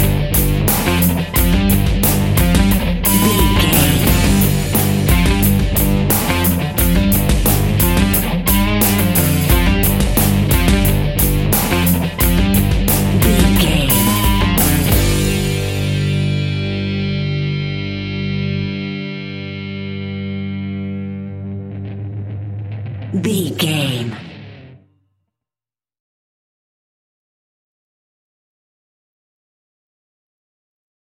Powerful Rock Music Track 15 Sec.
Epic / Action
Aeolian/Minor
instrumentals
Rock Bass
heavy drums
distorted guitars
hammond organ